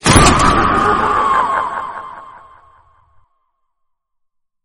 Lights_Turn_On.mp3